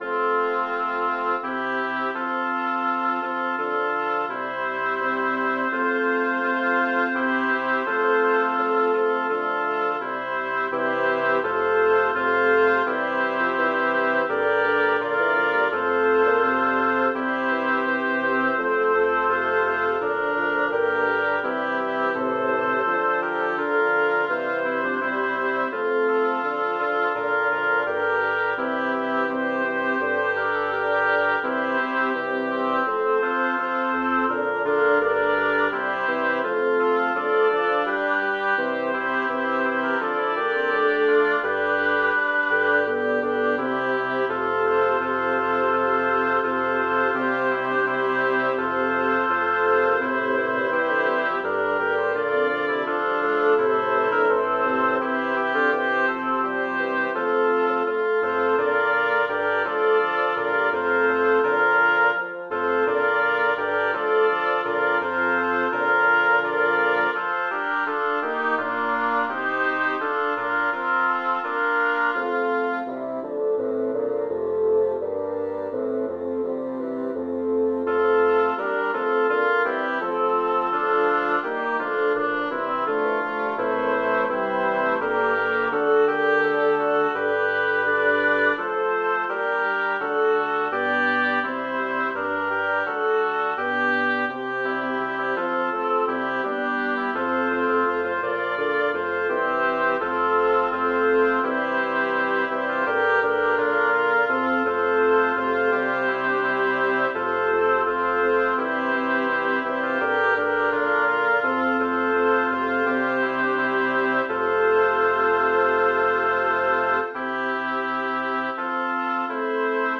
Title: Rallegratevi homai Composer: Alessandro Striggio Lyricist: Number of voices: 7vv Voicing: SSATTBB Genre: Secular, Madrigal
Language: Italian Instruments: A cappella